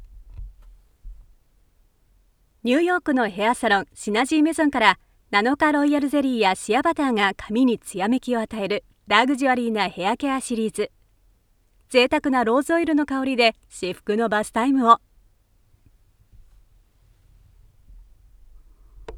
3. Commercial 00:19
If you’re looking for a voiceover that is based in japan i have many years of experience, and i can help you with your next project.